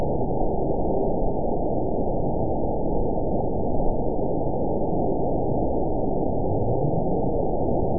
event 921846 date 12/19/24 time 23:09:30 GMT (4 months, 2 weeks ago) score 9.39 location TSS-AB02 detected by nrw target species NRW annotations +NRW Spectrogram: Frequency (kHz) vs. Time (s) audio not available .wav